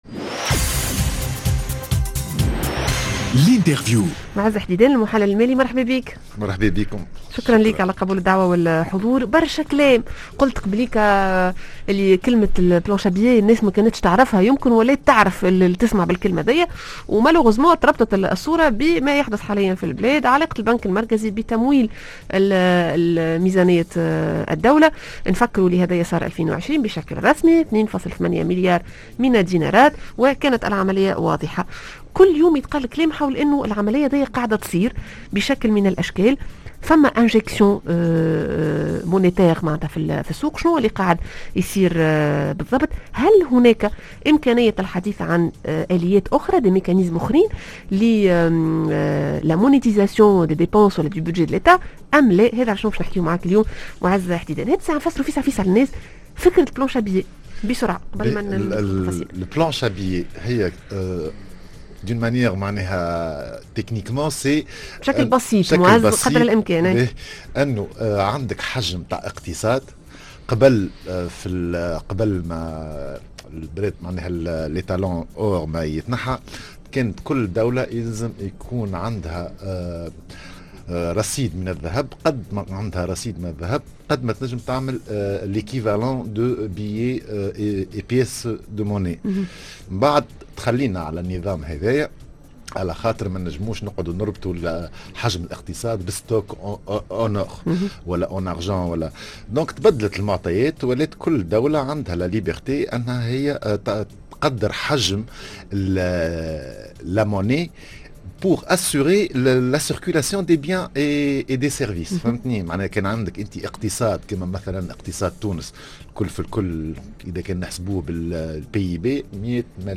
L'interview:وقتاش انجموا نحكيو على"planche à billets" و كيفاش يكون تأثيرها على الاقتصاد؟